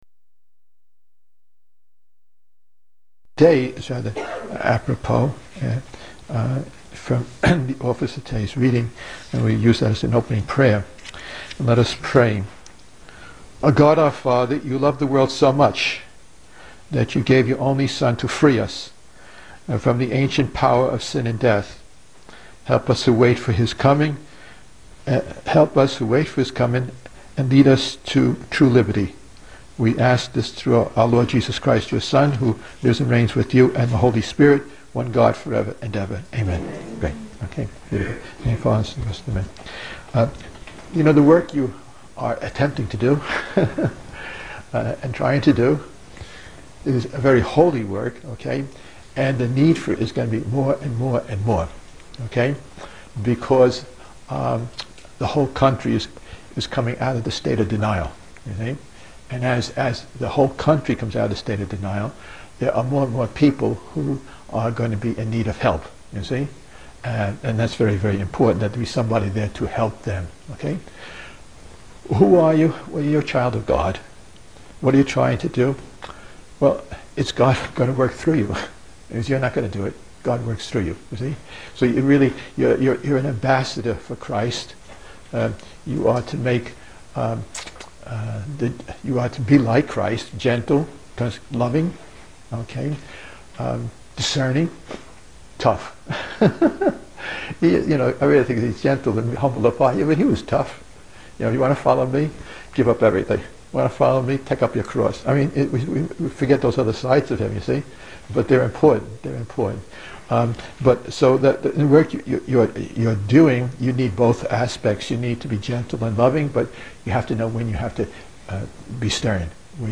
Talk to the Directors of Rachel's Helpers